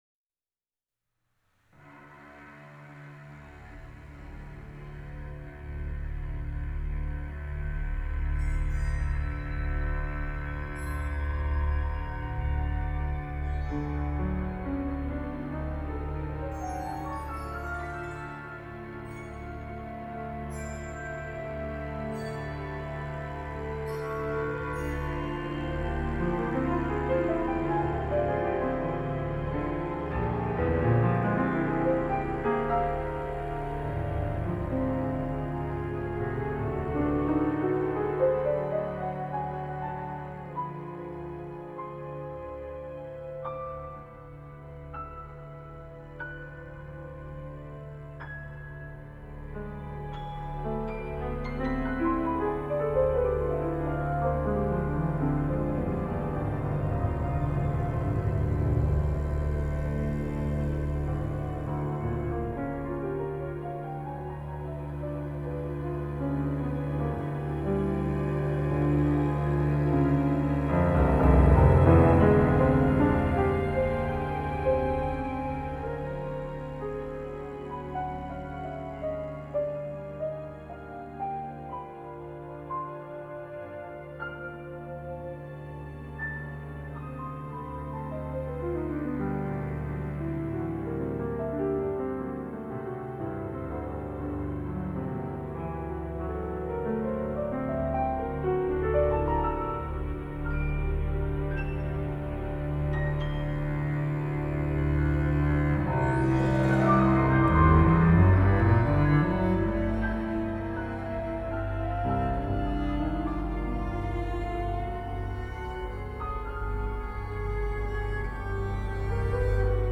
simply beautiful
Cellist